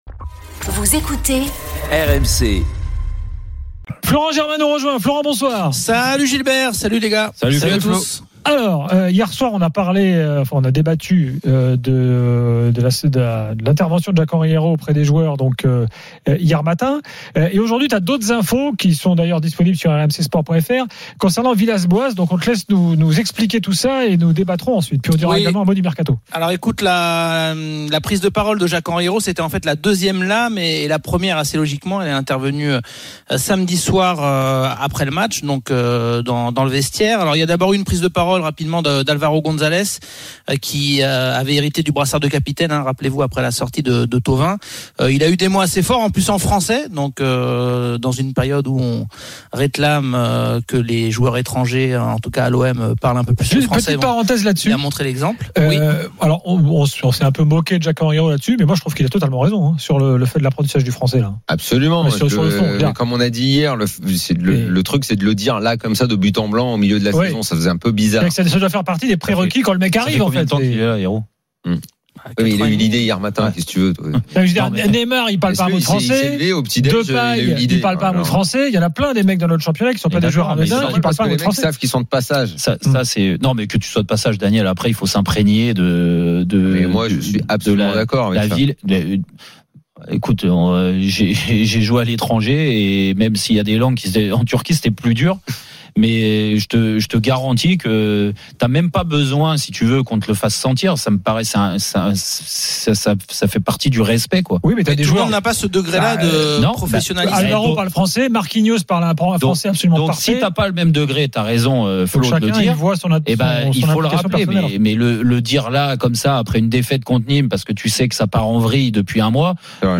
Chaque jour, écoutez le Best-of de l'Afterfoot, sur RMC la radio du Sport !
Live, résultats, interviews, analyses, ...